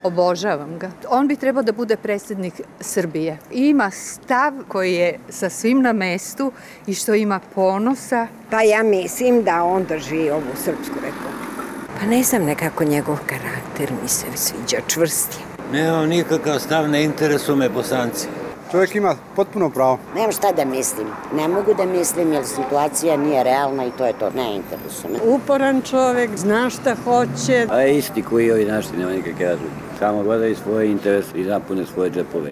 Građani o Dodiku